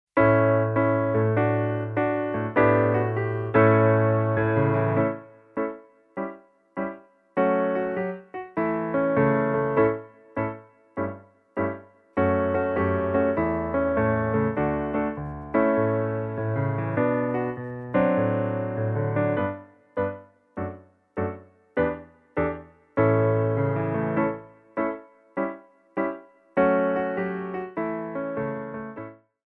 Piano Arrangements
SLOW TEMPO